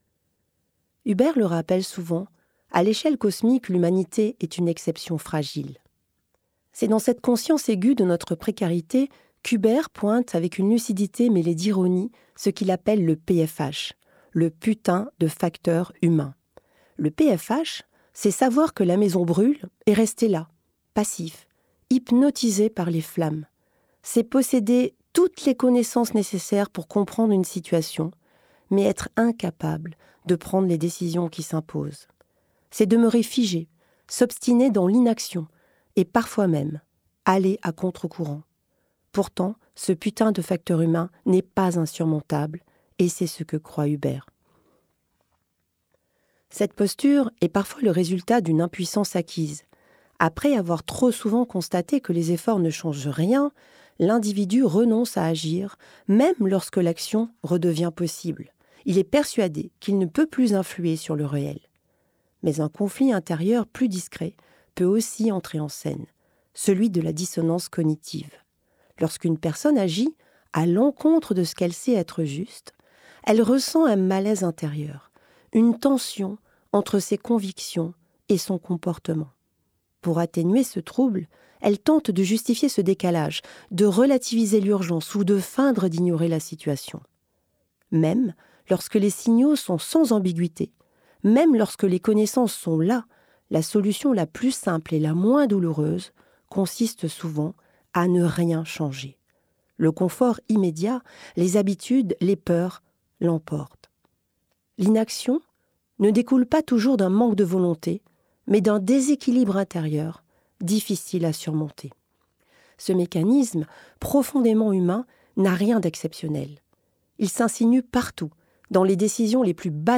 L'audiobook